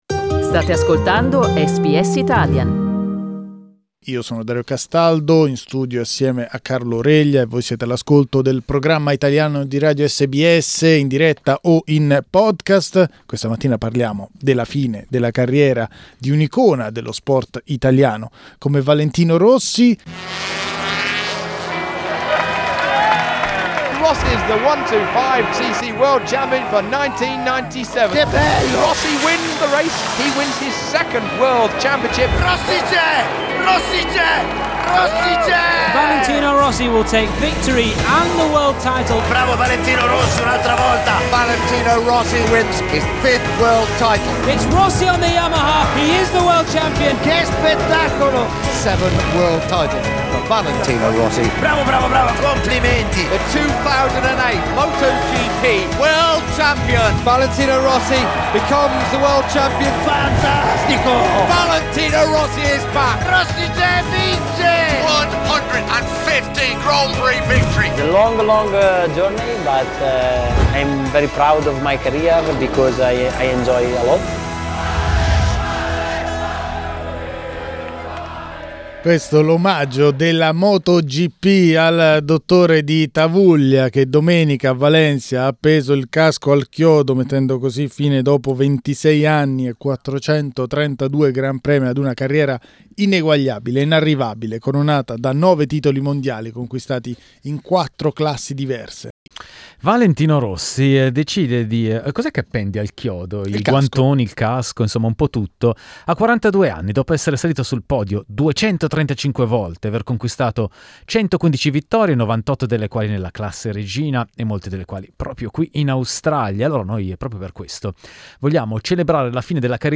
Per celebrare la fine della strepitosa carriera del Dottore, abbiamo chiesto ai nostri ascoltatori di raccontarci ricordi e aneddoti legati al fenomeno di Tavullia.